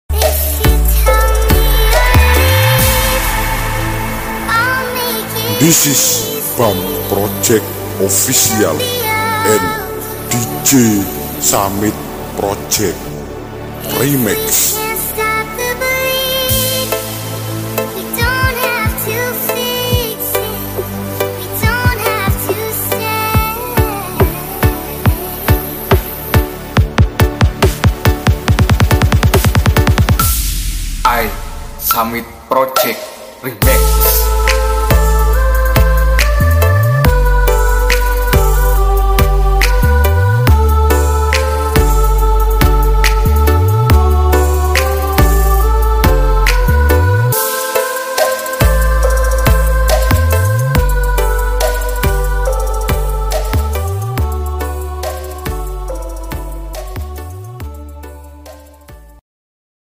slow trap